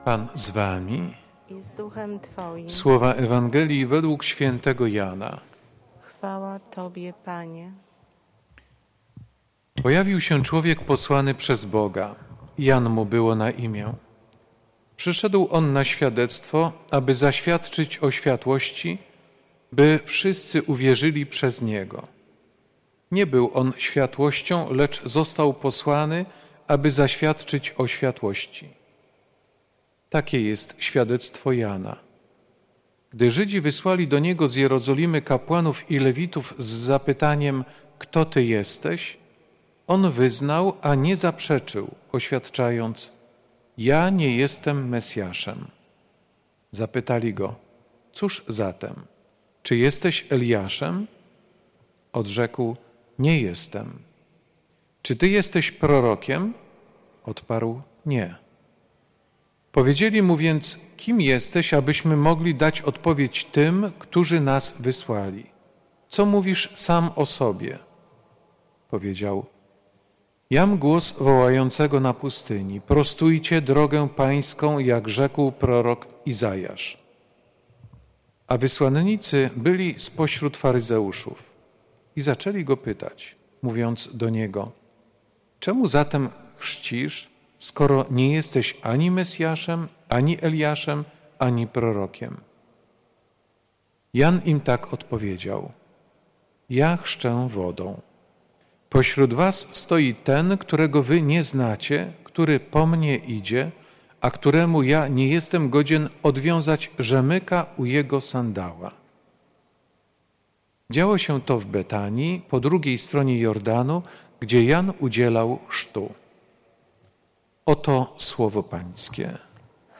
W poniedziałkowe południe 14 grudnia odprawiona została w Świątyni Opatrzności Bożej Eucharystia w intencji wszystkich Darczyńców, a także osób oraz instytucji – Przyjaciół Świątyni, którzy na różne sposoby wspomagali jej budowę i funkcjonowanie, zarówno w codziennej rzeczywistości, jak i podczas znaczących wydarzeń, odbywających się w tym miejscu w ciągu roku.